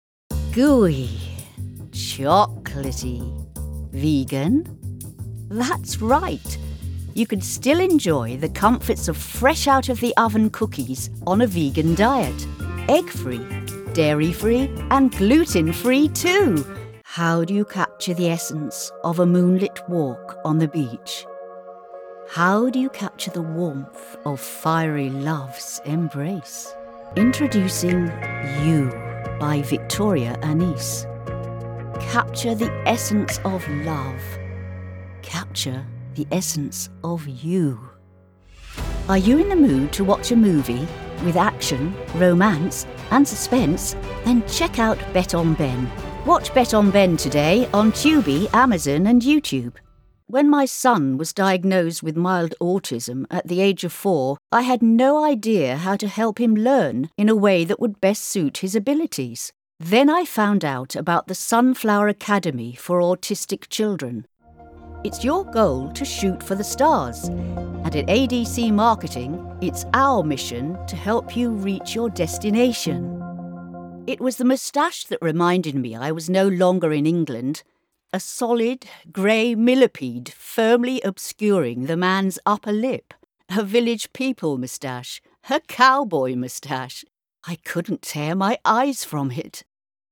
Authentic and native British speakers bring a professional, rich, refined and smooth sound to your next voice project.
British Voice Over Artists, Talent & Actors
Adult (30-50) | Older Sound (50+)